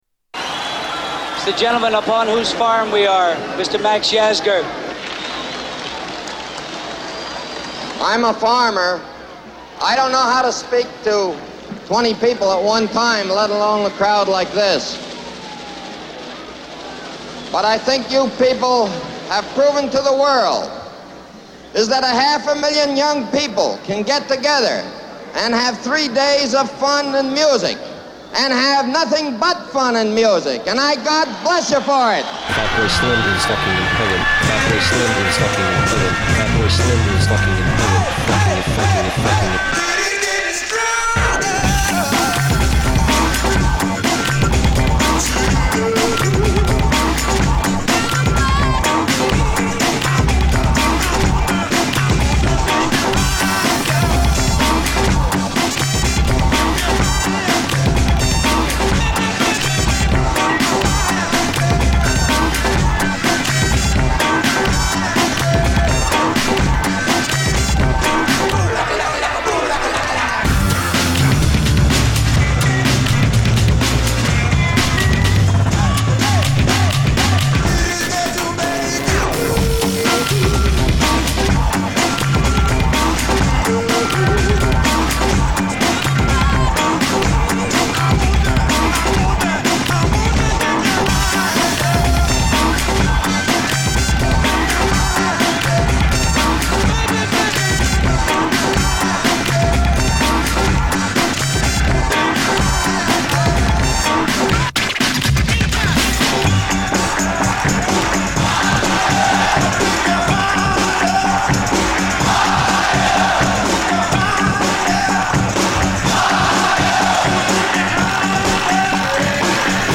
Mash Up music